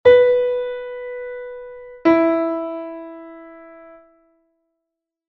Listening to ascending and descending intervals